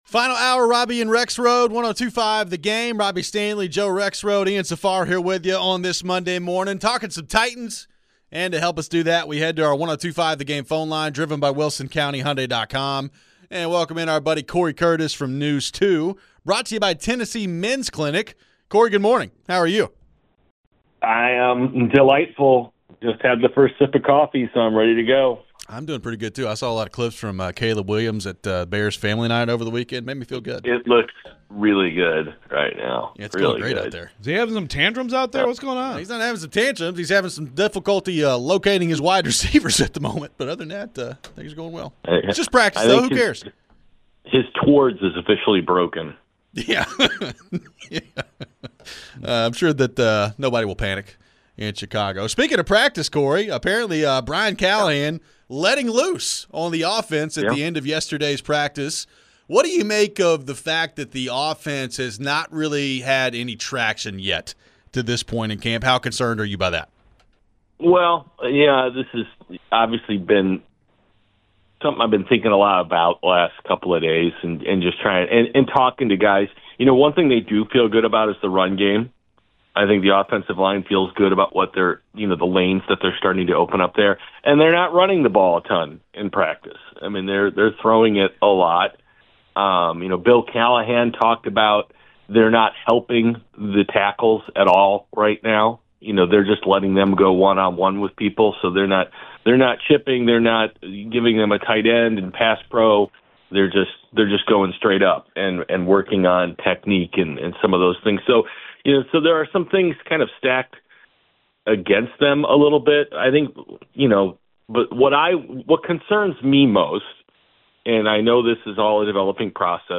We head back to the phones and react to some more Vols' fall camp stuff. We wrap up the show with final thoughts on the Titans.